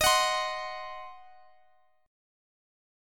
Listen to D#m6 strummed